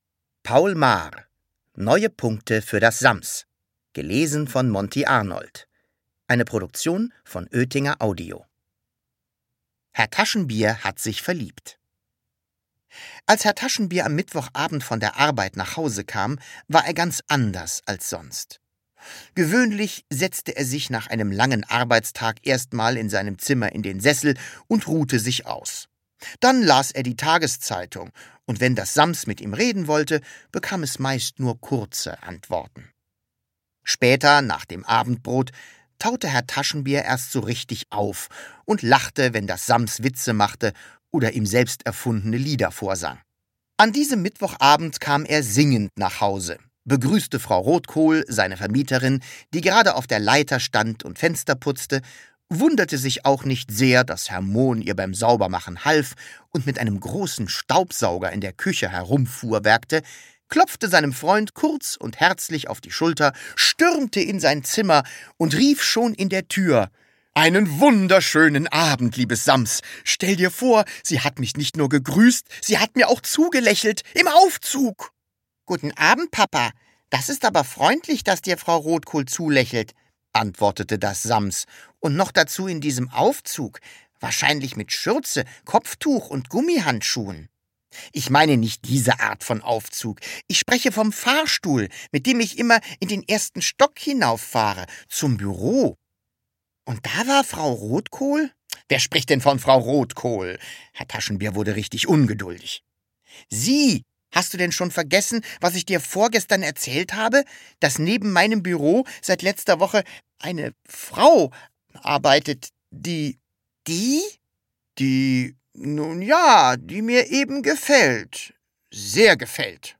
Hörbuch: Das Sams 3.